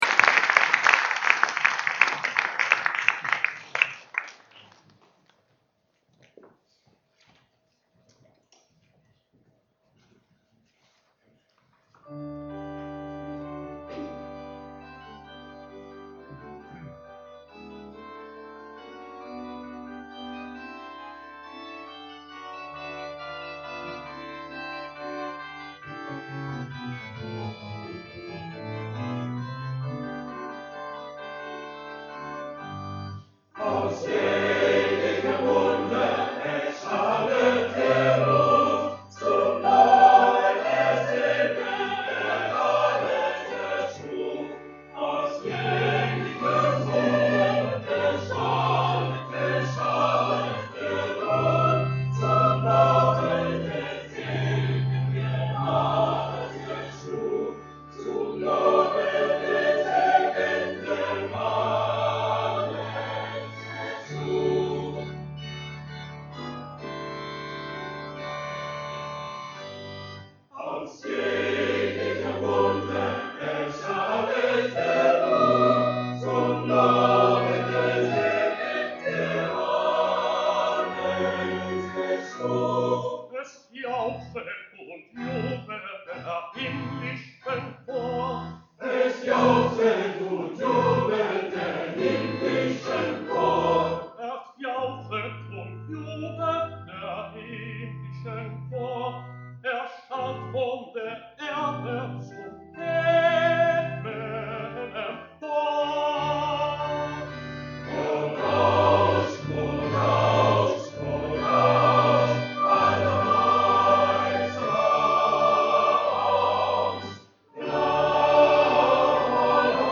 75th Anniversary Concert at Belsize Synagogue with its Professional Choir
organ
together with the community choir singing